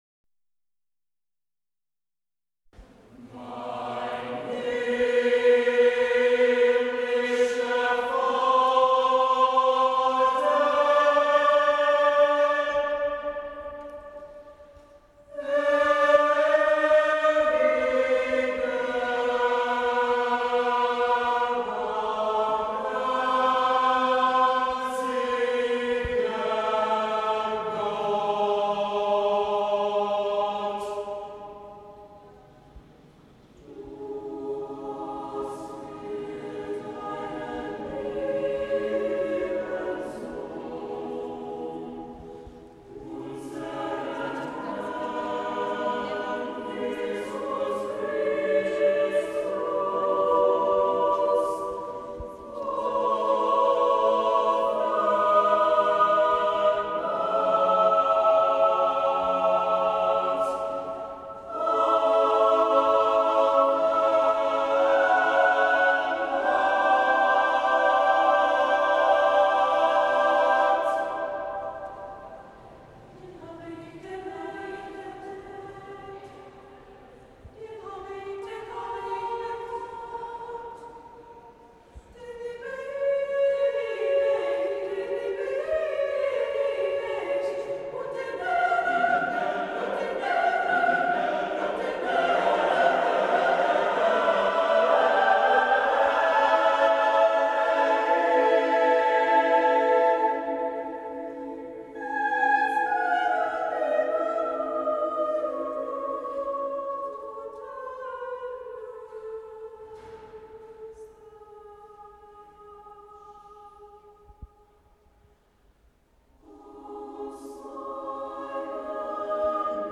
An informal performance at Luther’s grave.
SSAATTBB with Trombone or French Horn